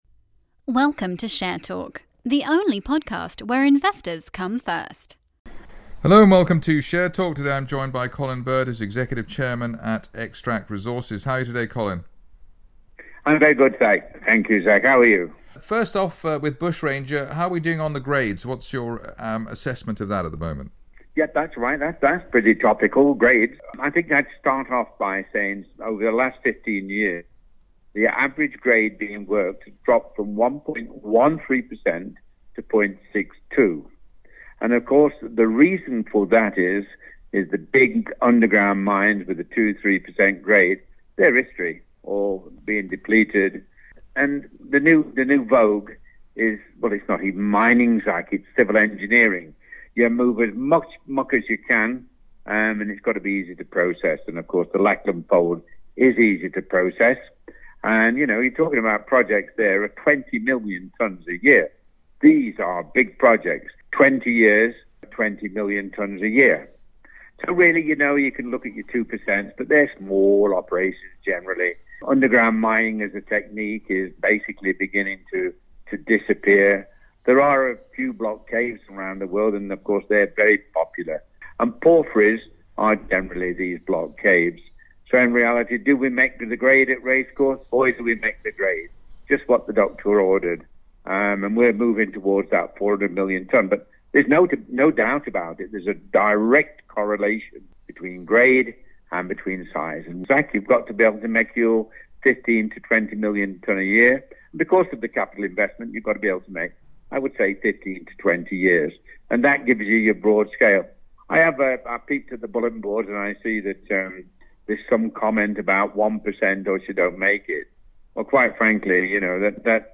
Exclusive Interview.